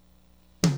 TOM1.WAV